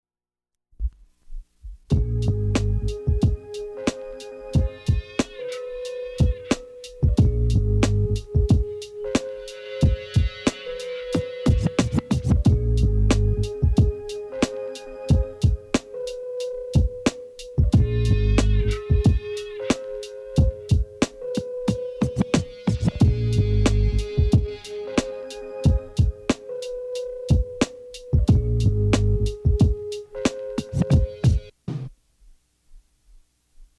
• Audiocassette